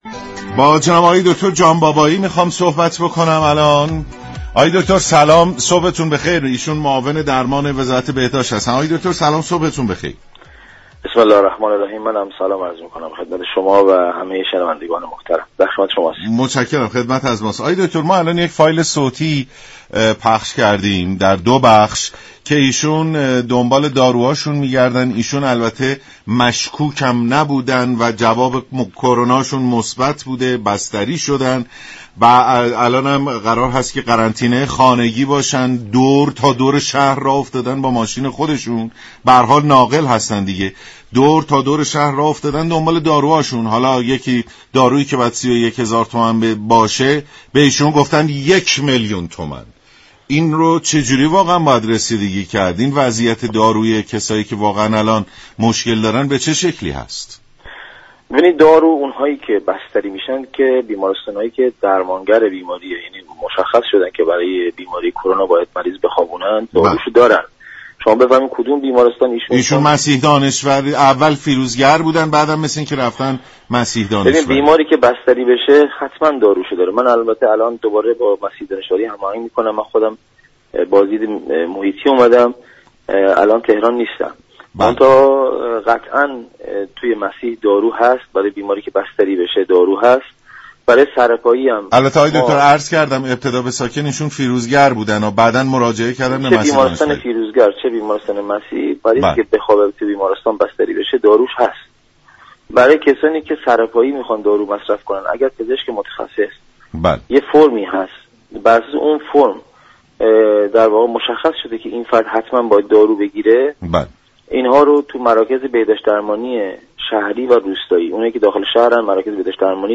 معاون درمان وزارت بهداشت، درمان و آموزش پزشكی گفت: بیماران سرپایی كه طبق تجویز پزشك ملزم به مصرف دارو هستند می توانند با مراجعه به مراكز بهداشت شهری و روستایی نسبت به تهیه داروی خود اقدام نمایند.